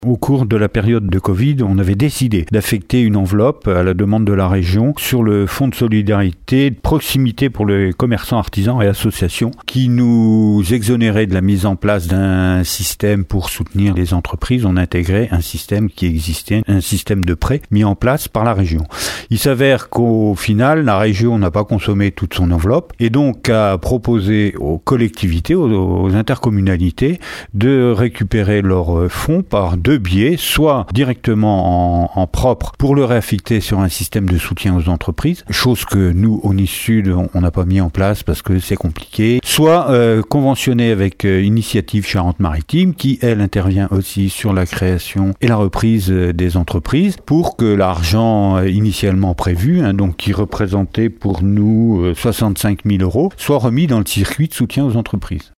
Les précisions de Jean Gorioux, président de la CdC Aunis Sud :